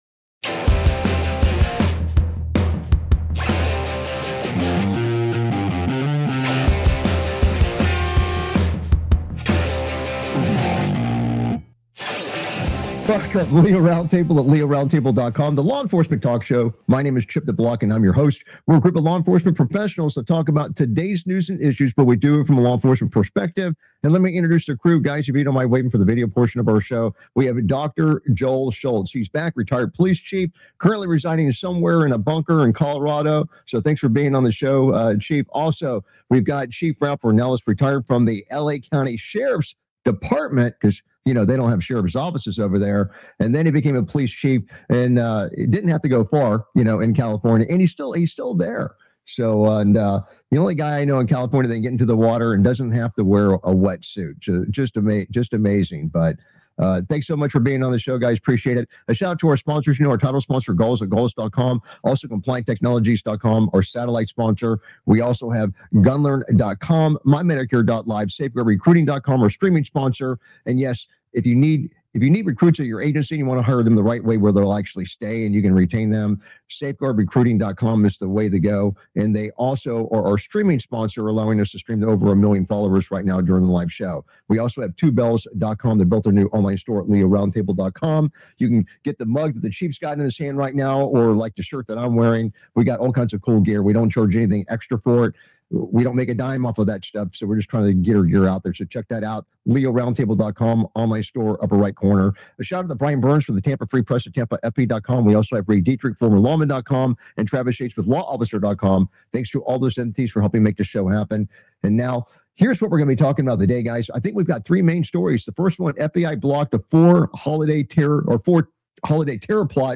Talk Show Episode, Audio Podcast, LEO Round Table and S11E055, The Trump FBI Has Been Busy Disrupting Terrorism Plots Across The Country! on , show guests , about The Trump FBI has been busy disrupting terrorism plots across the country,Trump lashes back at former counterterrorism chief who resigned,DHS funding freeze presents big trouble for airlines,Murder suspect fatally shot in fire fight at the end of a pursuit,Cop begs to escape fleeing vehicle as suspect grabs for his gun,2025 Counterterrorism Milestones and National Security Shifts,Global Security & Frontline Crisis,Critical Incident Analysis,Shootouts and Vehicle Struggles,Aviation Crisis and TSA Funding, categorized as Entertainment,Military,News,Politics & Government,National,World,Society and Culture,Technology,Theory & Conspir